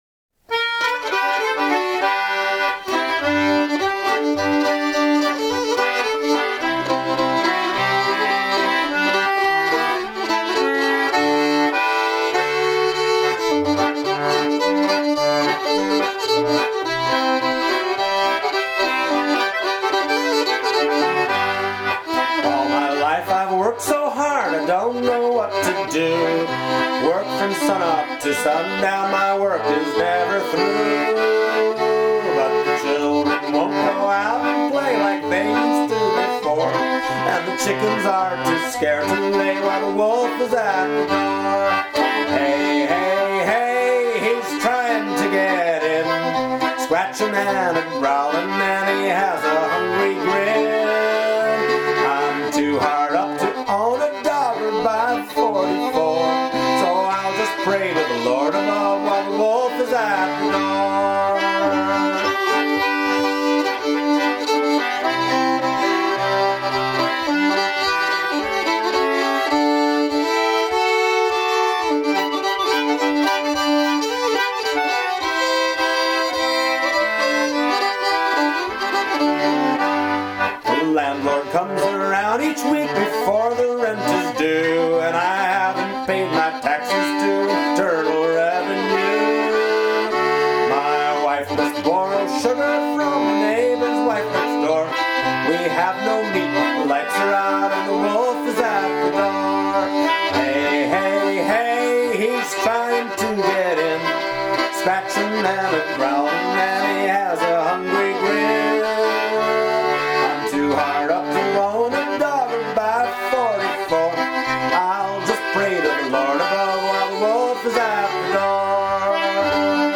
old-time fiddling